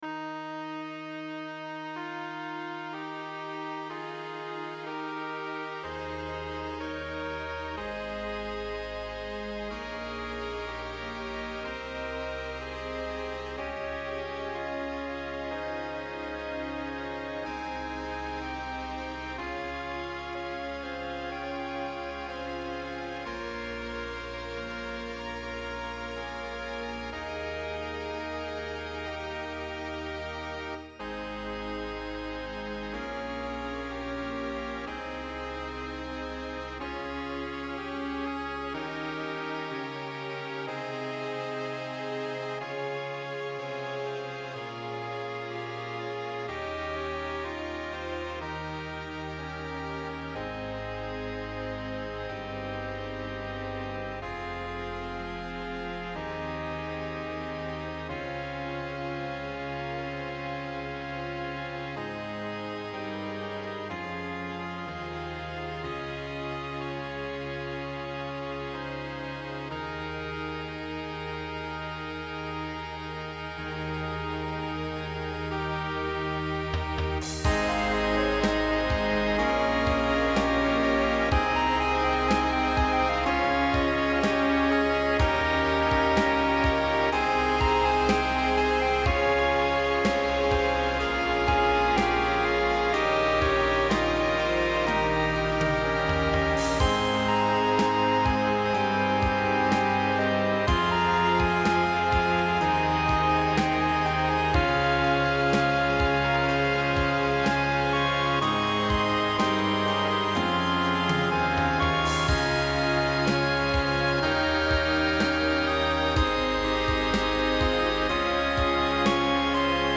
TROMBONE SOLO
TROMBONE SOLO • ACCOMPAGNAMENTO BASE MP3